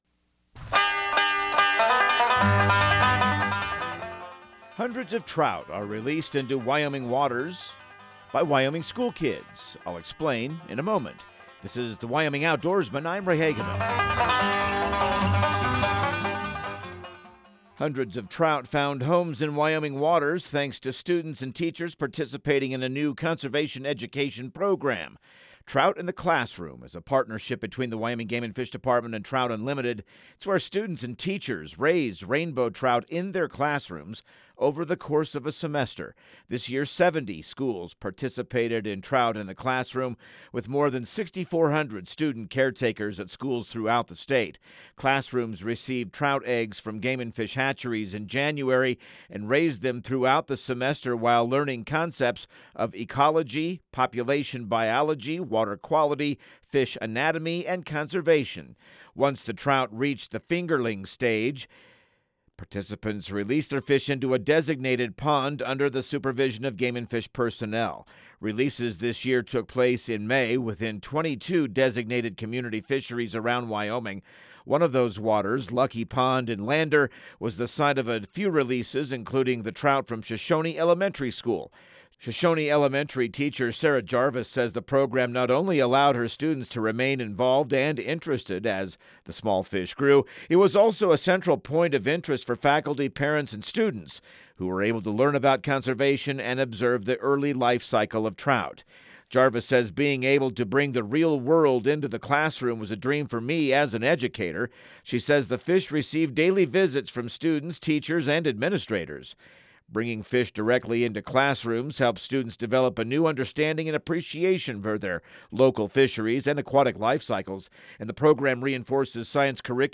Radio news | Week of June 16